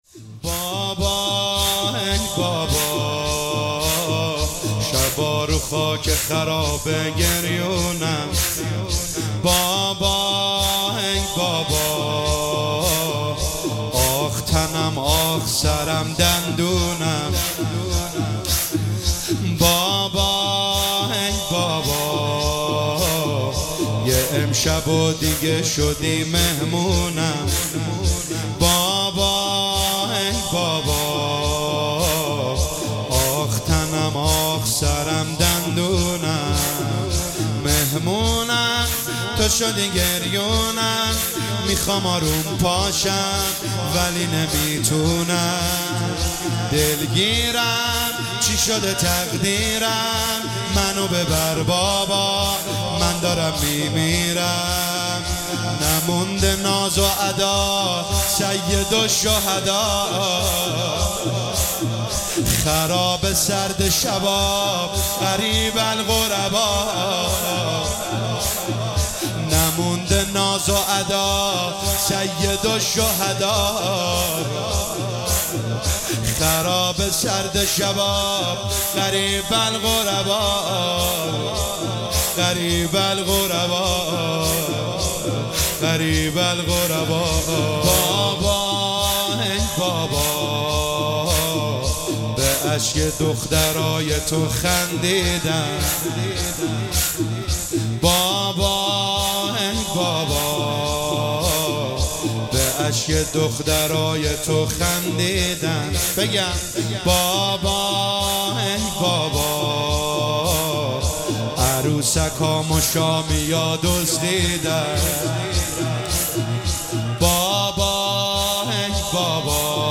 هیئت ام ابیها قم